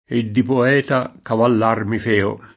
cavallaio [kavall#Lo] s. m.; pl. ‑lai — anche cavallaro [kavall#ro]; e solo cavallaro nell’ant. sign. di «messaggero a cavallo»: E di poeta cavallar mi feo [